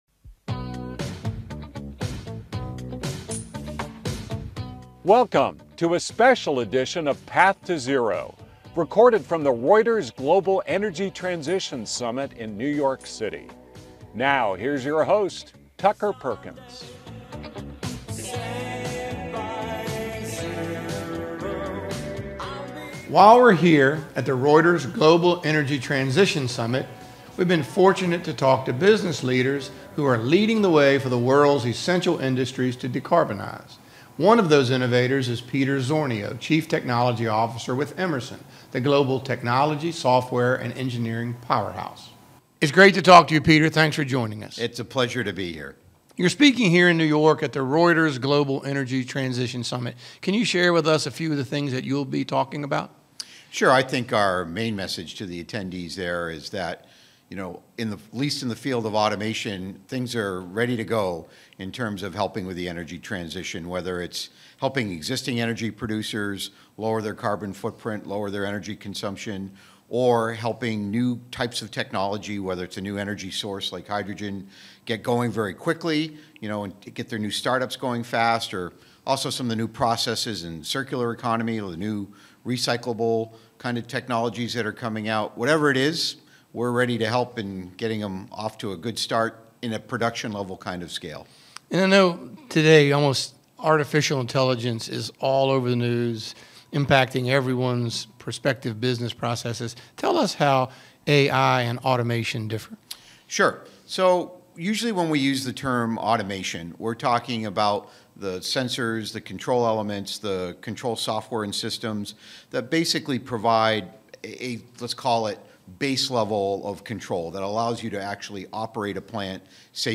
At the Reuters Global Energy Transition Summit